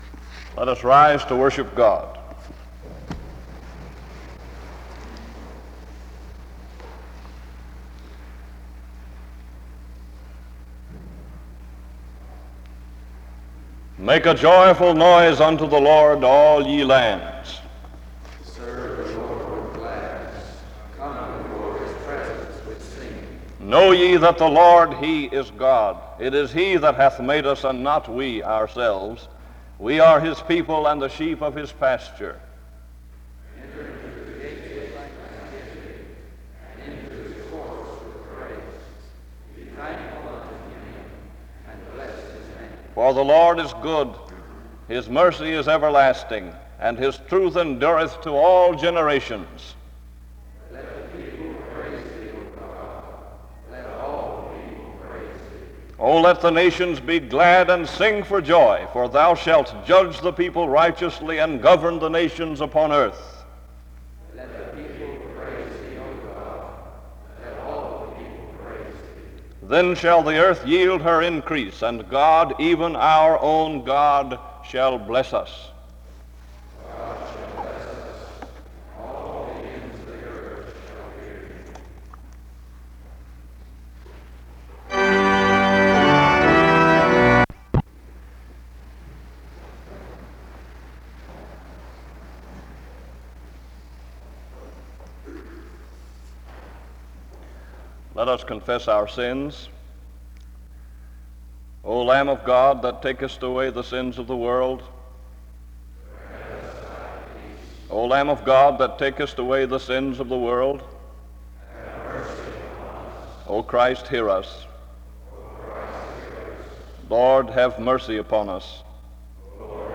The service begins with a responsive reading (0:00-2:10). Afterwards, there is a time of instrumental music and prayer (2:11-6:35).
Chapels
Wake Forest (N.C.)